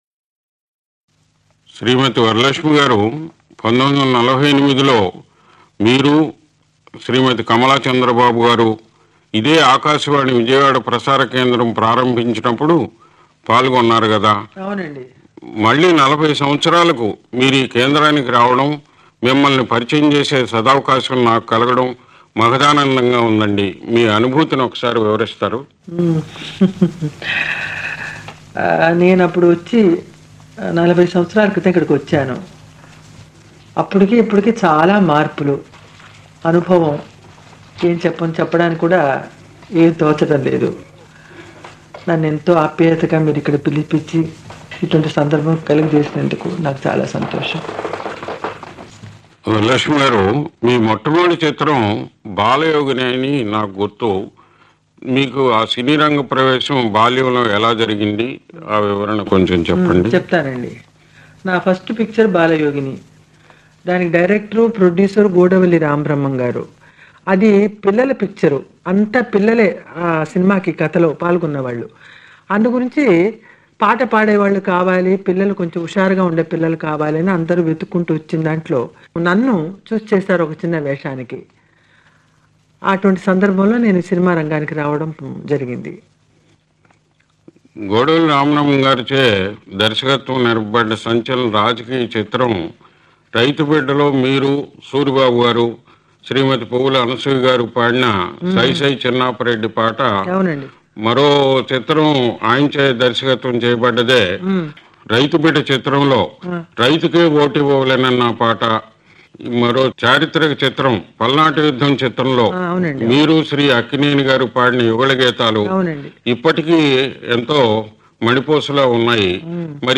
అలనాటి నటి ఎస్. వరలక్ష్మితో ముఖాముఖీ